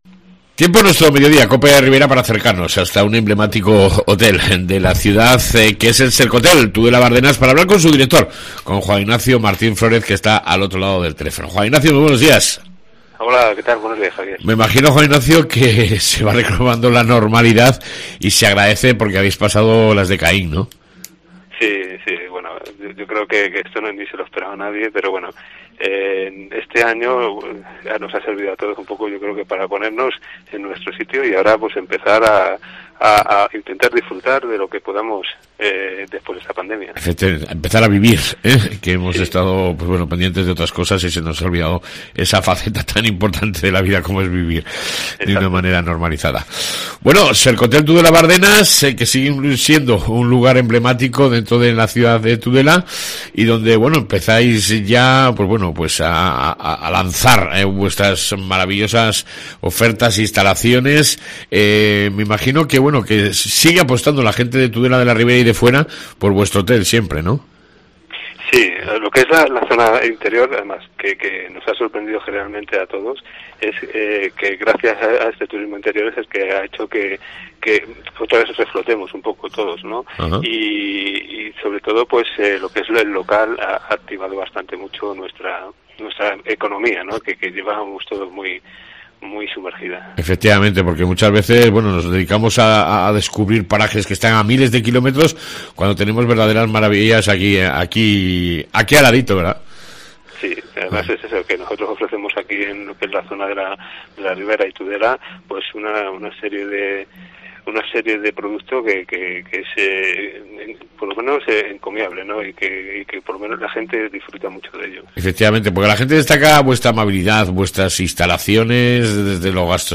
AUDIO: ENTREVISTA CON SERCOTEL TUDELA BARDENAS